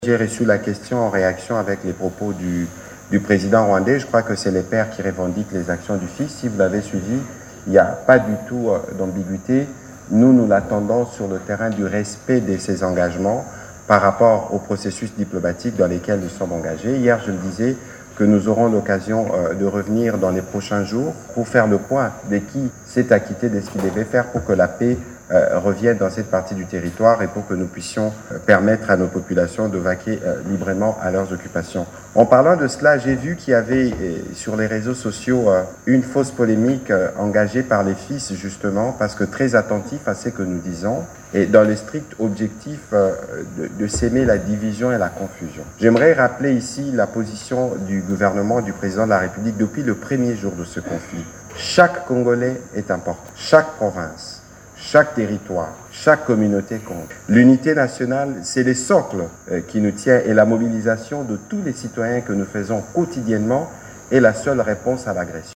Pour Patrick Muyaya, porte-parole du gouvernement, Paul Kagame est « le géniteur, le père du groupe rebelle M23 », accusé de semer la terreur dans l’Est de la RDC :